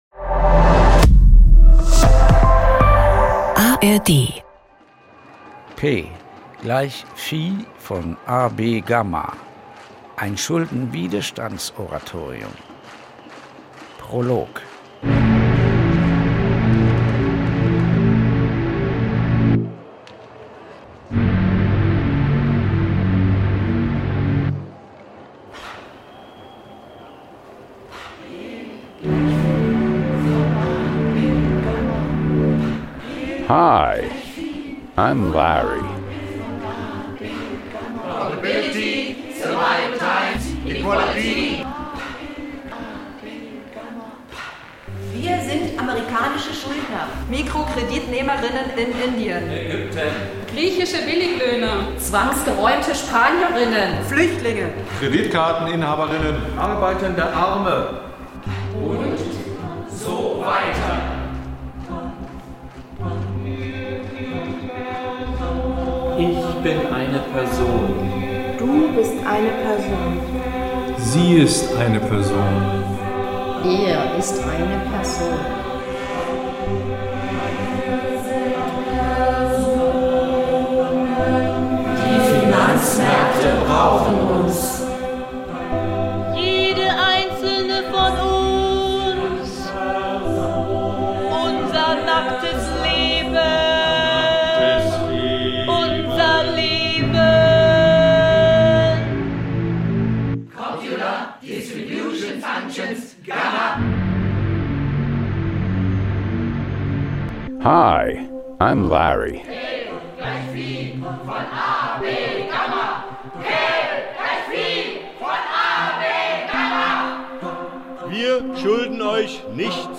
Wir werden die Stimmen der Schuldner und der Gläubiger hören und die Stimmen der Politiker, die die Bedingungen für den neuen Schuldenkapitalismus geschaffen haben. Wir werden sie alle hören in einem Oratorium von Schulden, Schuld und Widerstand.